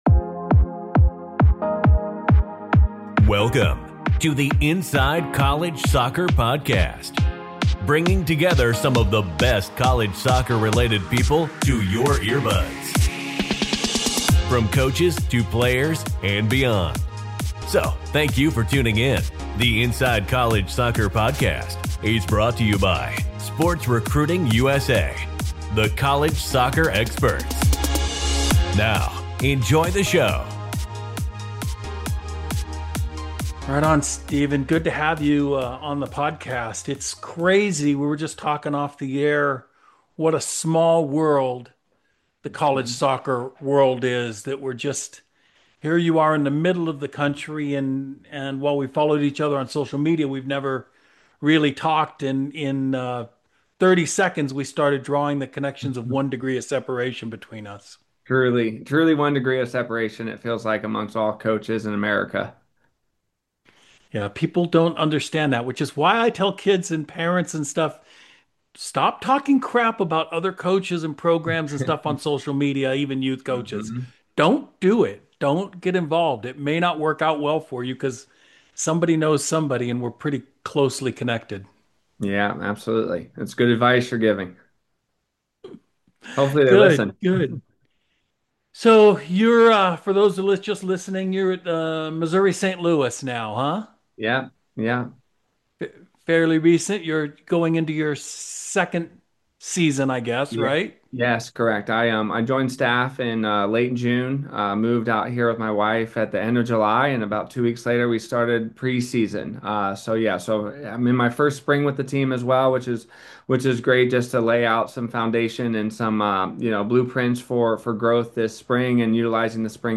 Jimmy Conrad and Charlie Davies sit down with their former coach - and one of the most influential figures in American soccer history - Bob Bradley for a wide-ranging conversation as the USMNT gears up for a home World Cup (04:03). Bob evaluates Mauricio Pochettino's time in charge of the group and what fine-tuning is left to do (05:59). The crew gets into the weeds on shape, flexibility, and how to maximize the March friendlies (14:10).